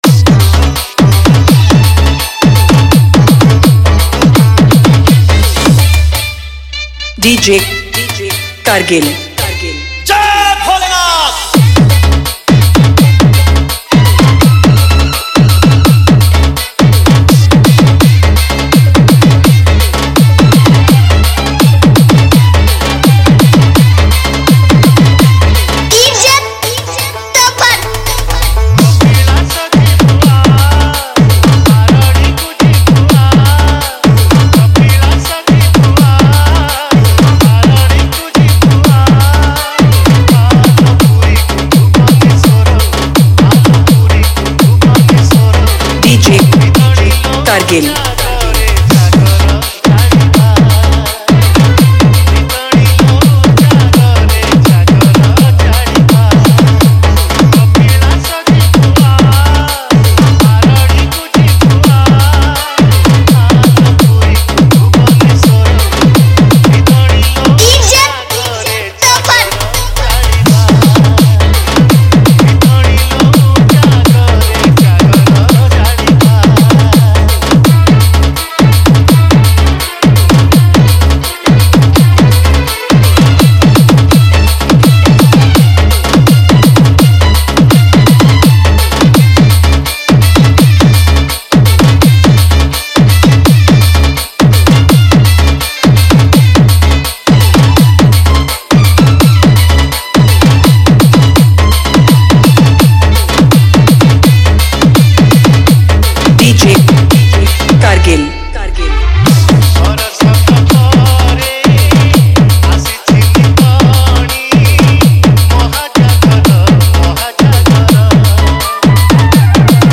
Category:  Odia Bhajan Dj 2020